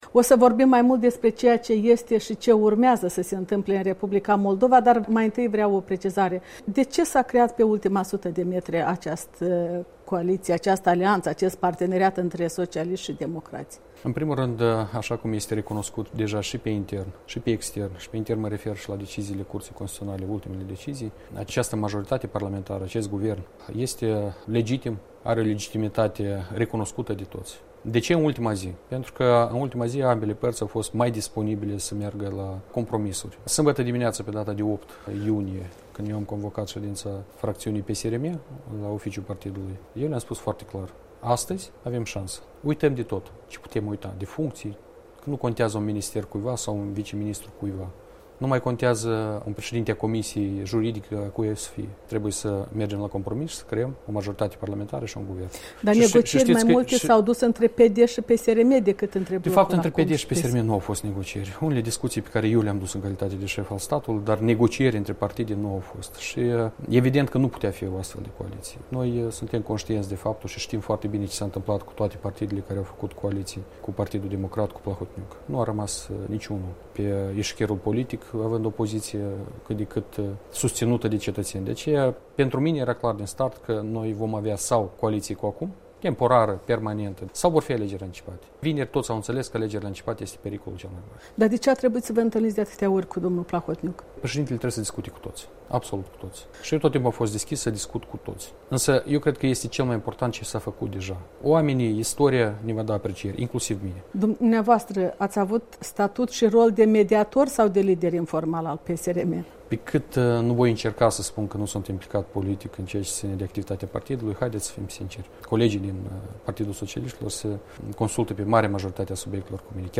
Un interviu cu președintele statului despre perspectivele și imperativele menținerii actualei majorități parlamentare și a guvernului Maia Sandu.